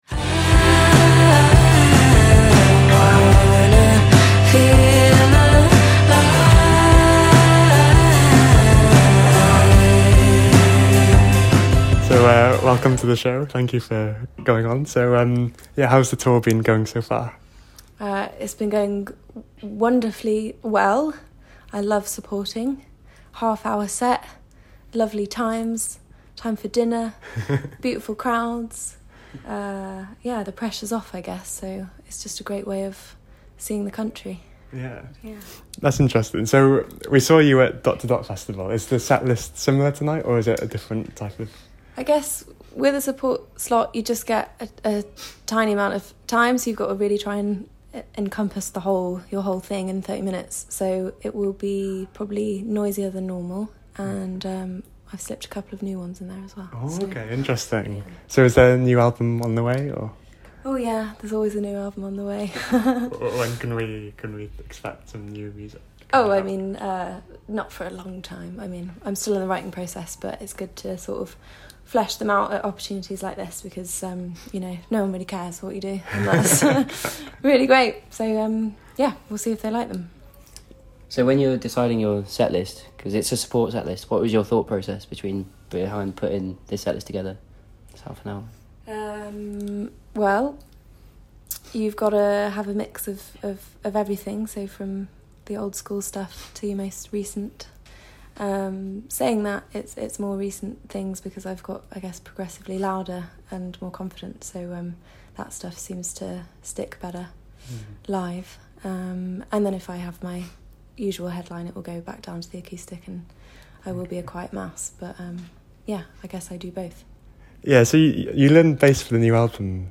Billie Marten Interview